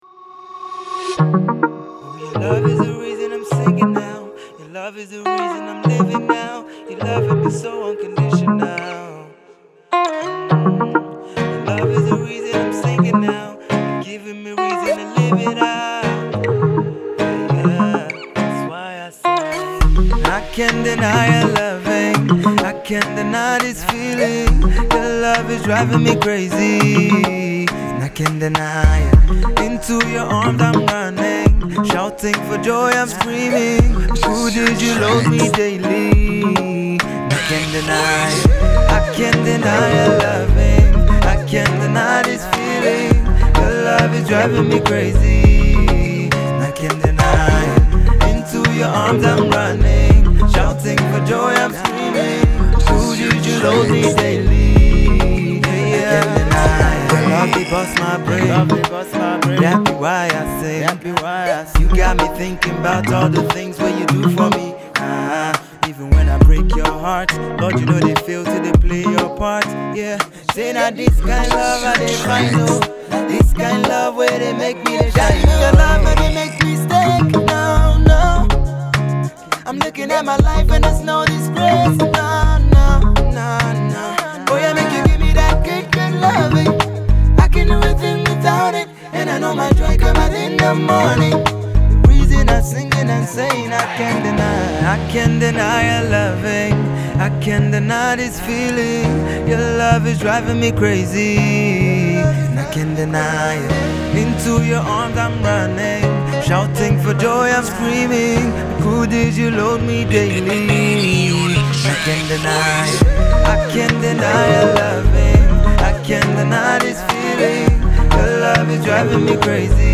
has a groovy Afropop bounce to it